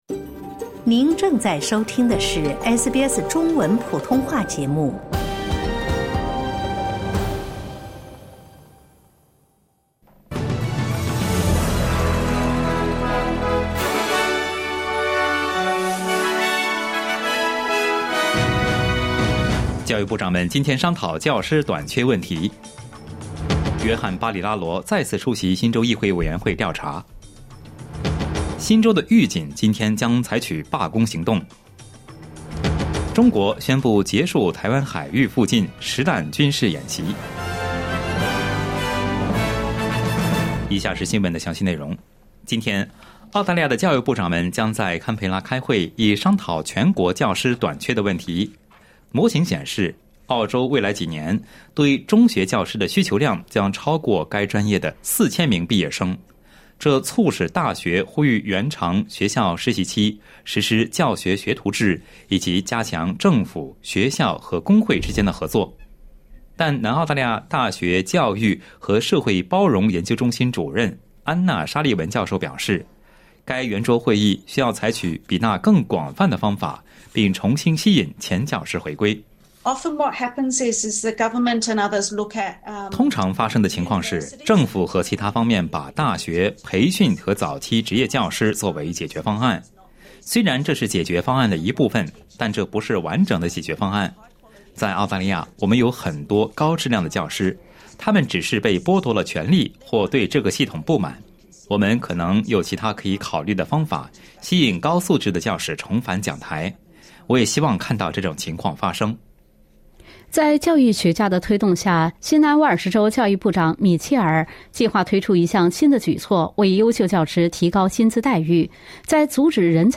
SBS早新闻（8月12日）